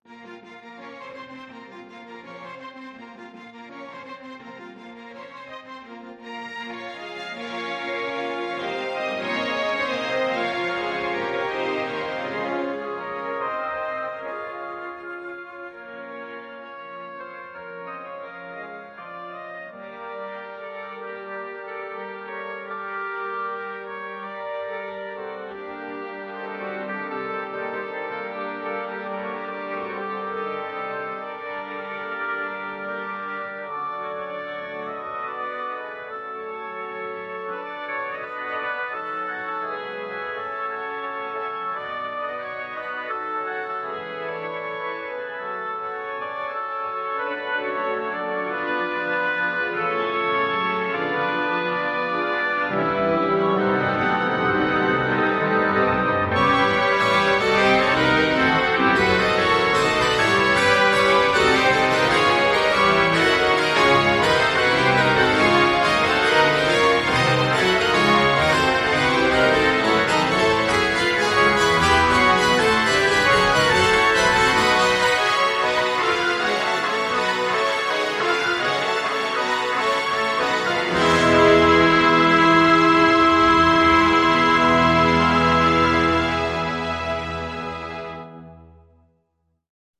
orchestra_0513_2.mp3